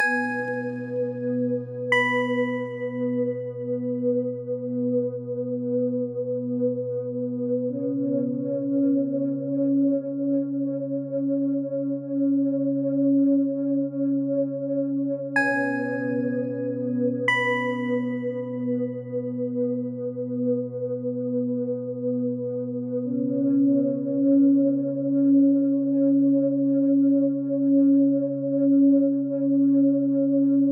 ループ：◎
BPM：125 キー：G# ジャンル：ゆったり 楽器：オルゴール、シンセサイザー